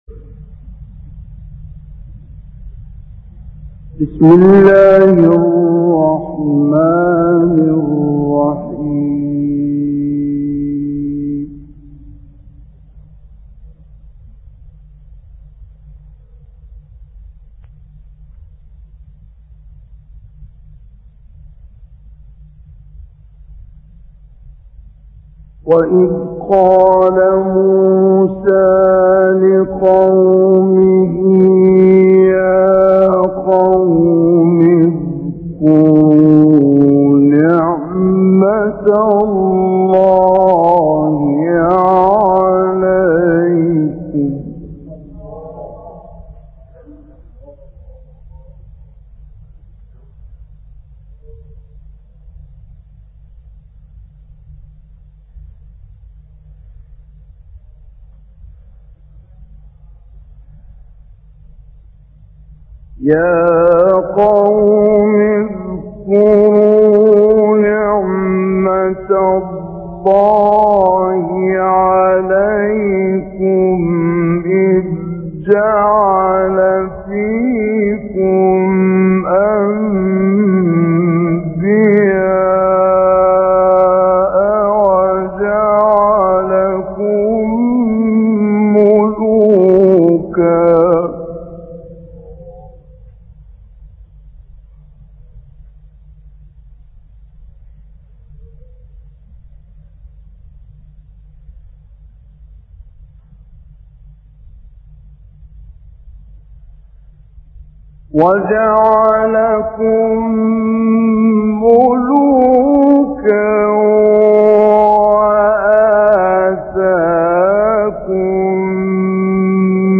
این تلاوت‌ها که در سال‌های مختلف در مسجد امام حسین(ع) شهر قاهره اجرا شده، در سحرگاه و قبل از اذان صبح از رادیو پخش شده است.